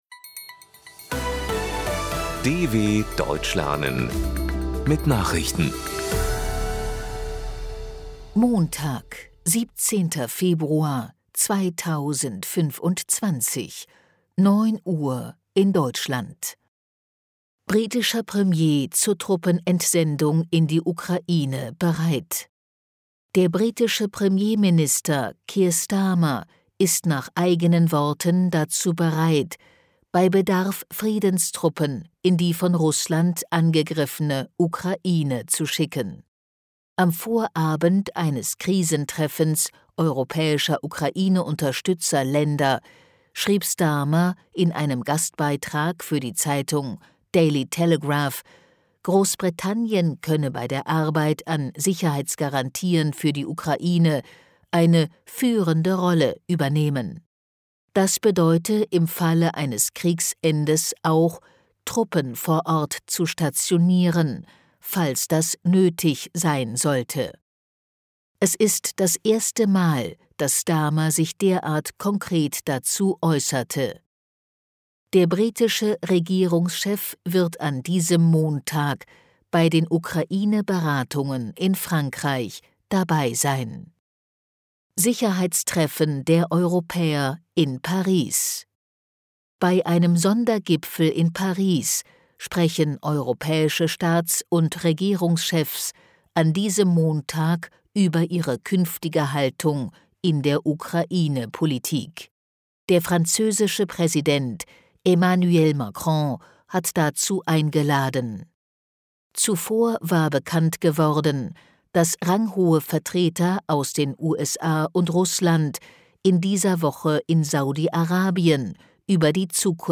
Die Langsam gesprochenen Nachrichten der DW bieten von Montag bis Samstag aktuelle Tagesnachrichten aus aller Welt. Das langsam und verständlich gesprochene Audio trainiert das Hörverstehen.